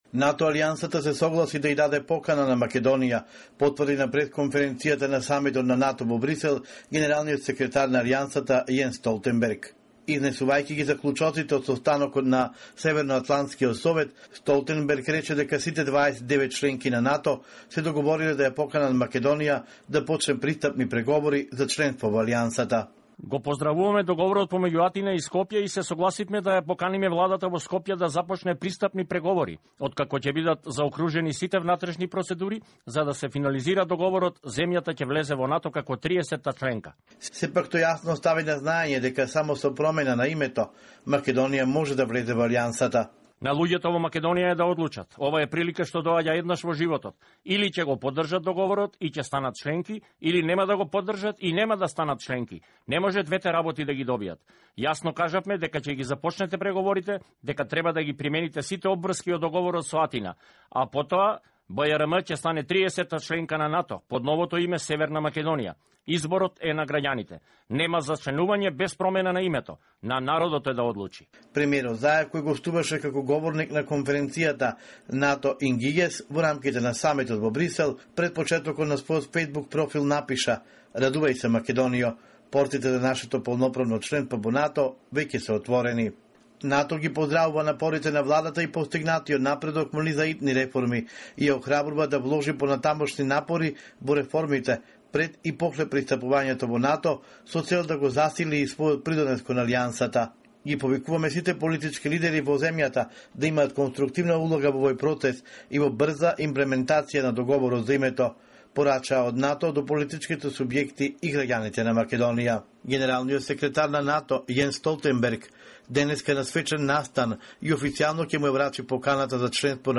NATO Secretary General Jens Stoltenberg made it clear that only by changing the name could Macedonia join the Alliance. Here is an excerpt of the press conference at which Mr. Stoltenberg announced that Macedonia will be admitted to NATO under the name Republic of Northern Macedonia.